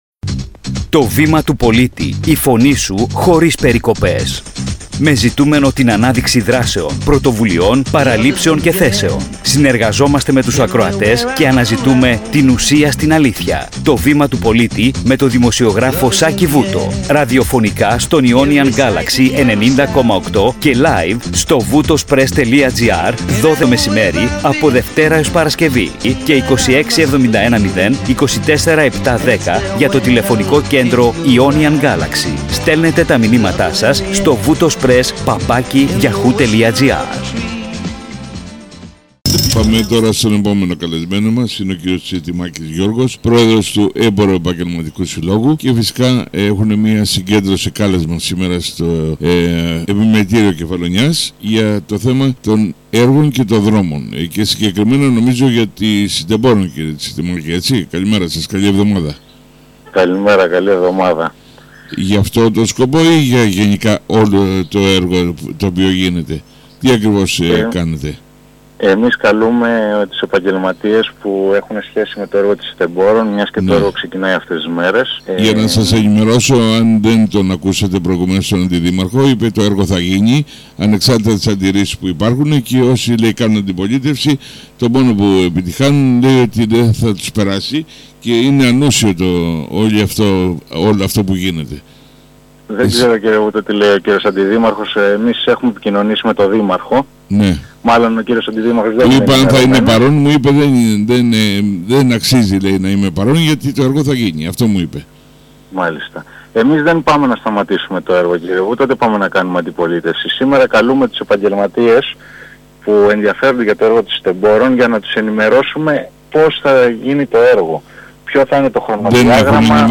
Η συνέντευξη